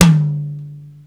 • Long Room Reverb Tom One Shot D Key 36.wav
Royality free tom one shot tuned to the D note. Loudest frequency: 1261Hz
long-room-reverb-tom-one-shot-d-key-36-HL7.wav